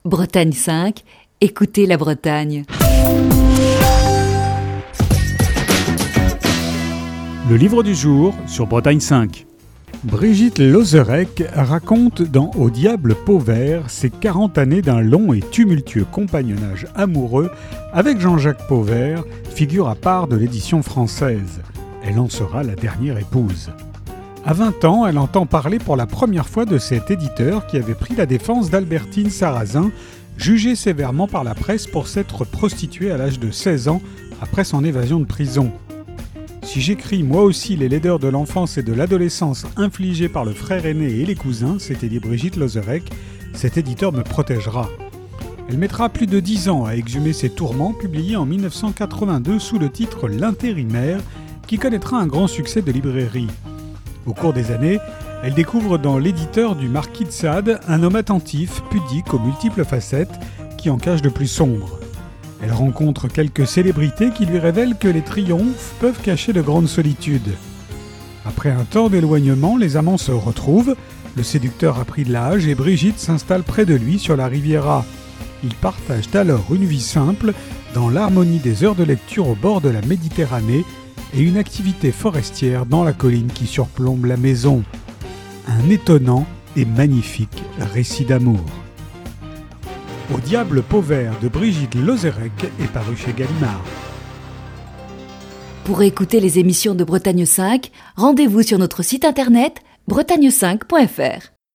Chronique du 2 août 2021.